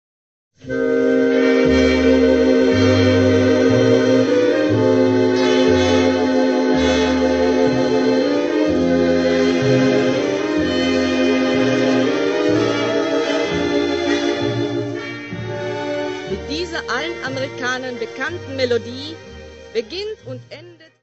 Music Category/Genre:  Jazz / Blues